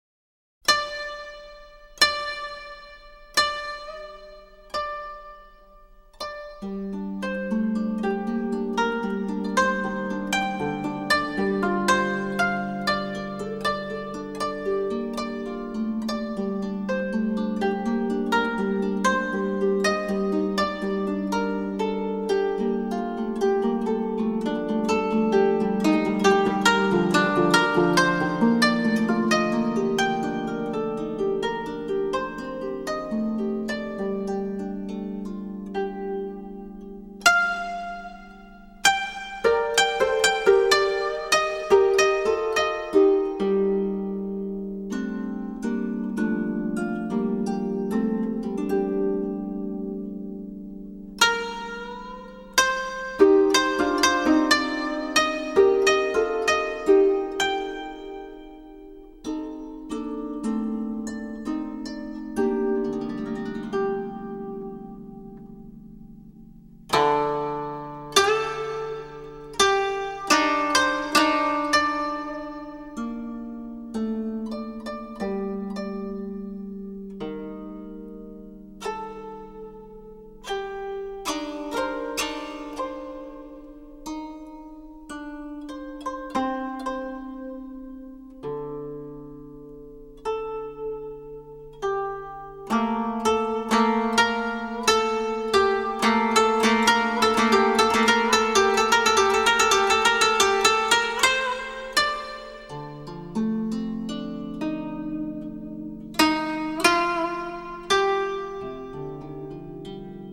★ 融會貫通東西方樂器、傳統與現代的跨時代完美樂章！
★ 輕柔紓緩的美麗樂音，兼具令人震撼感動的發燒音效！
樂風涵蓋傳統、世界、電子或 New Age